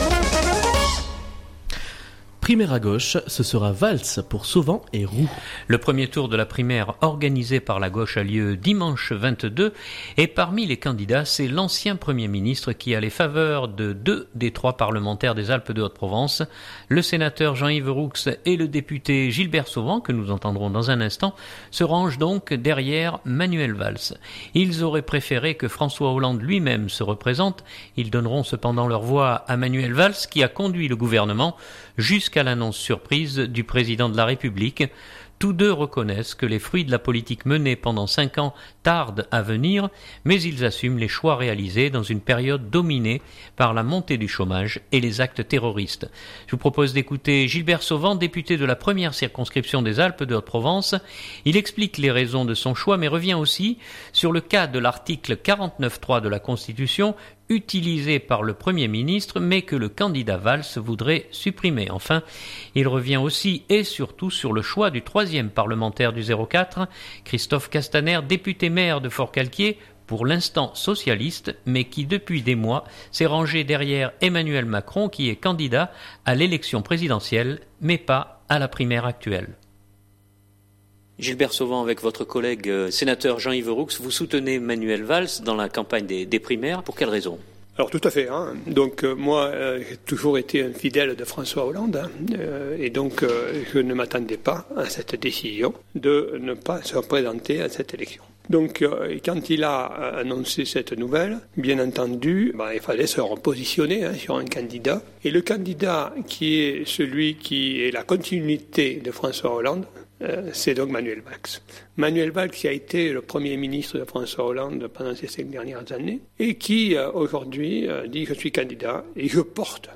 Je vous propose d’écouter Gilbert Sauvan, député de la 1ère circonscription des Alpes de Haute-Provence. Il explique les raisons de son choix, mais revient aussi sur le cas de l’article 49.3 de la Constitution utilisé par le premier ministre mais que le candidat Valls voudrait supprimer.